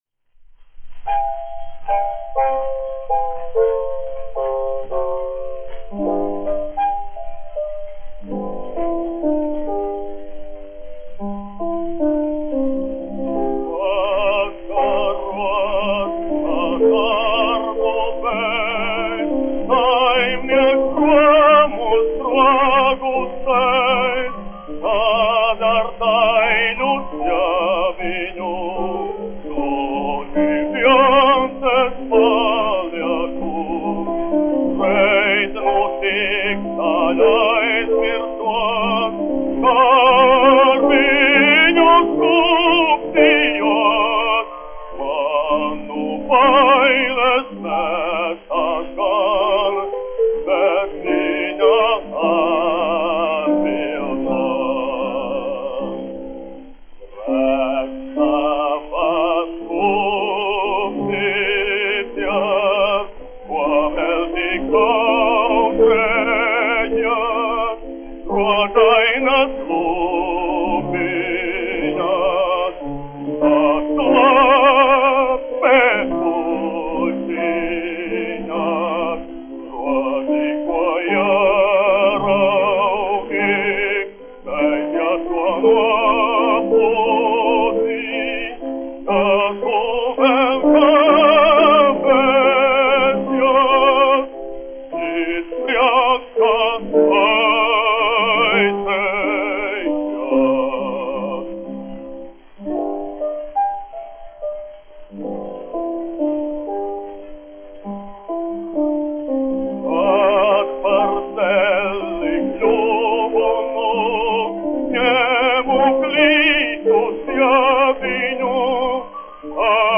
Kaktiņš, Ādolfs, 1885-1965, dziedātājs
1 skpl. : analogs, 78 apgr/min, mono ; 25 cm
Operas--Fragmenti, aranžēti
Skaņuplate
Latvijas vēsturiskie šellaka skaņuplašu ieraksti (Kolekcija)